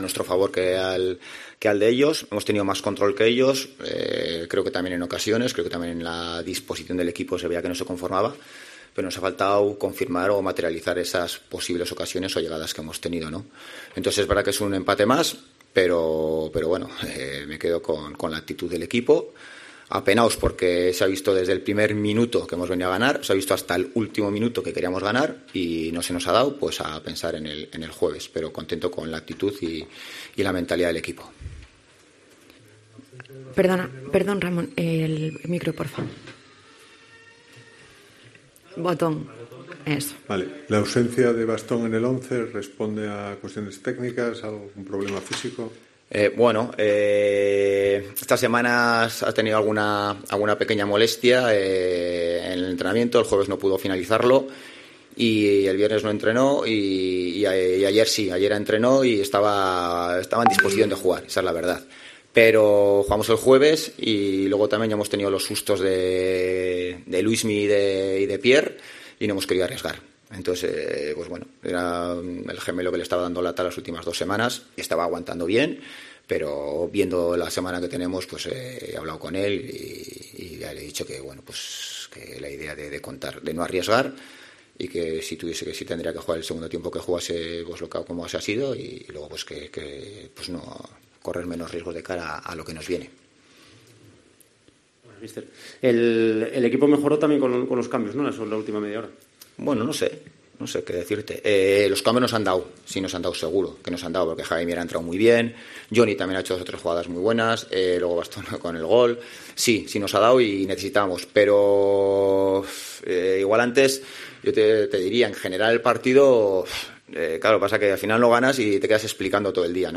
Rueda de prensa Ziganda (Sanse-Oviedo)